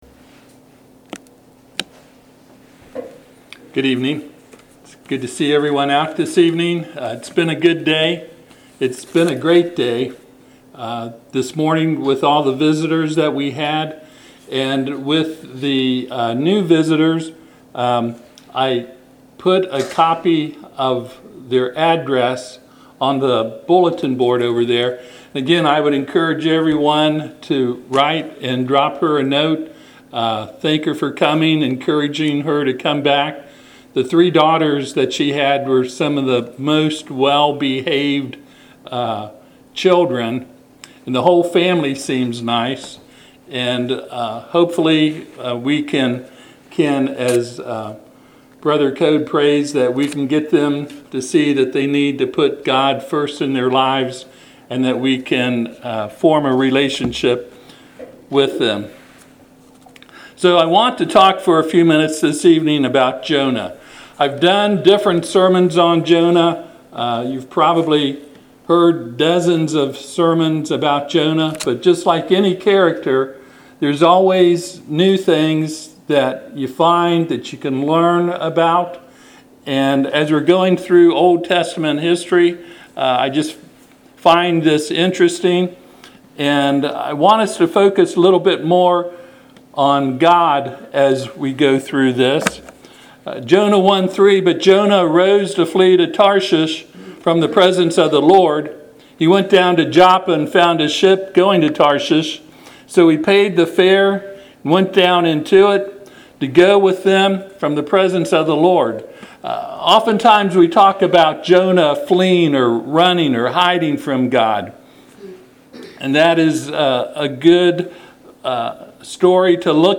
Passage: Jonah 1:3 Service Type: Sunday PM